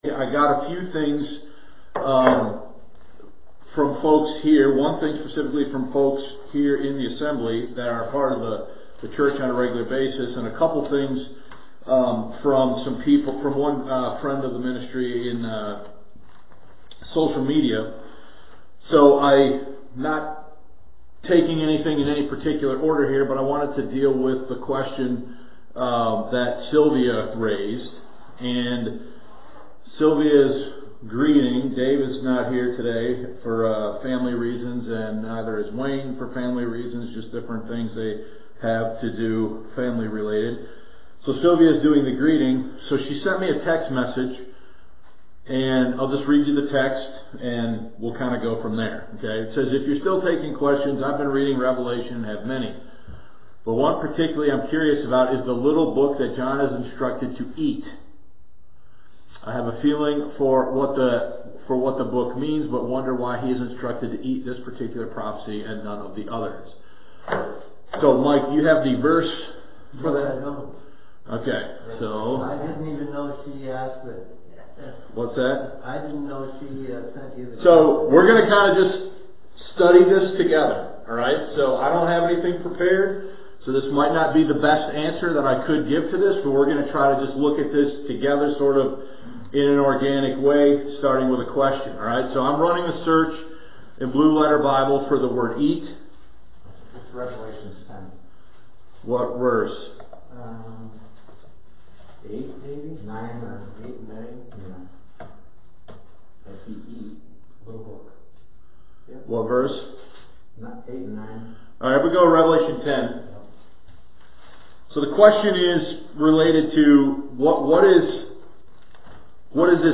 Adult Sunday School Q&A: The “Little Book” Of Revelation 10